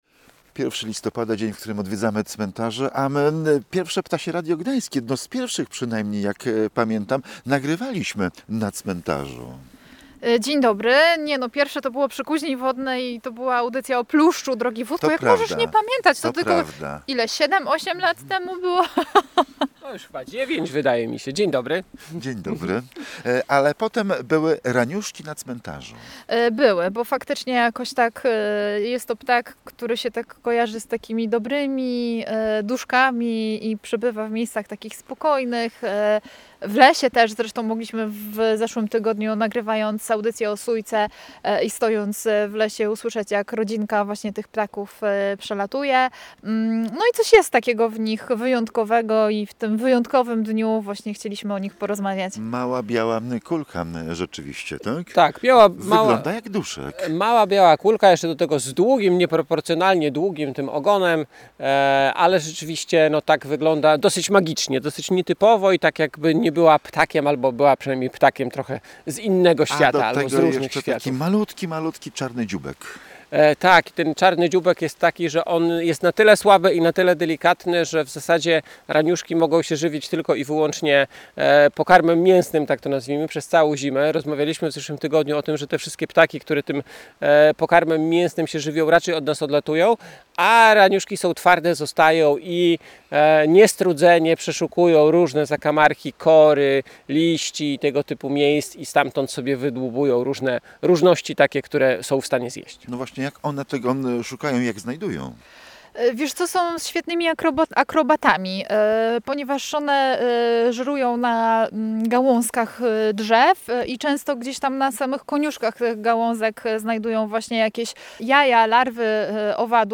ptasie-raniuszki.mp3